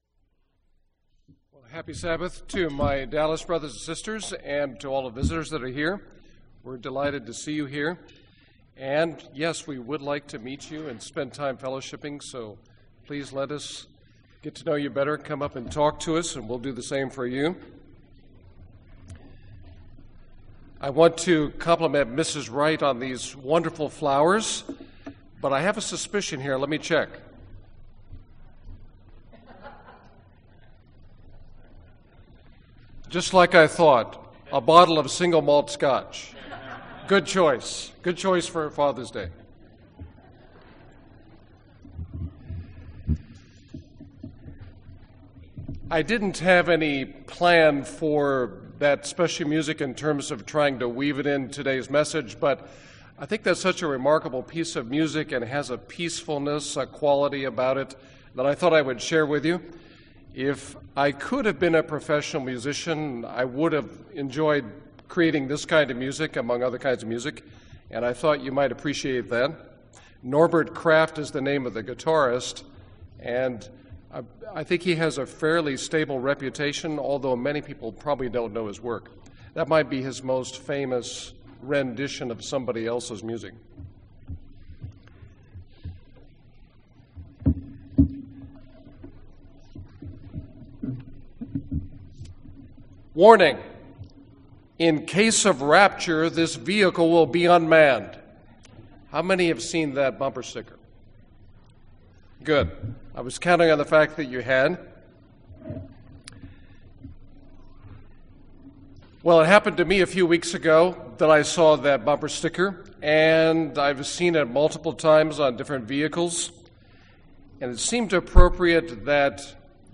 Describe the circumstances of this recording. Given in Dallas, TX